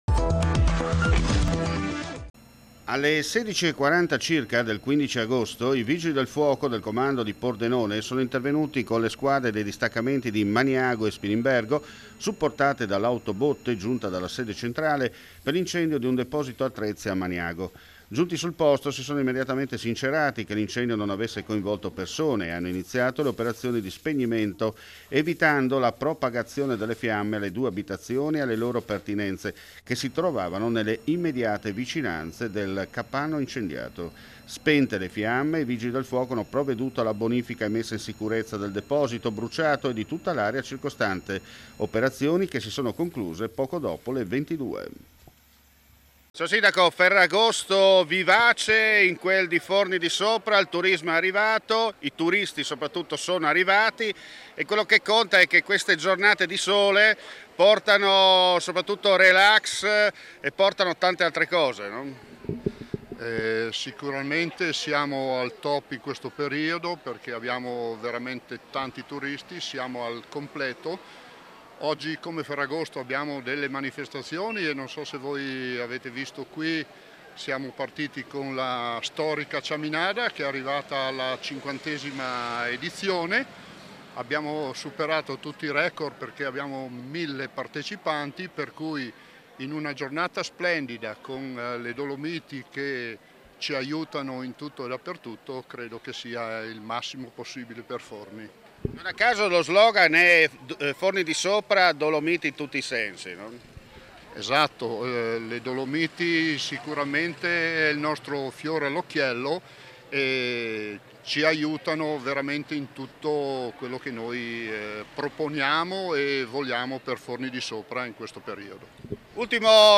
FRIULITV GIORNALE RADIO: LE ULTIME NOTIZIE DAL FRIULI VENEZIA GIULIA